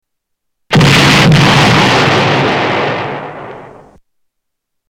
Explosion with echo